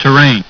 The MK VIII is an Enhanced Ground Proximity Warning System aimed at regional turboprop and small turbofan aircrafts such as the Citation, Citation Bravo, B1900D, Beechcraft 99 and L410.
terrain.wav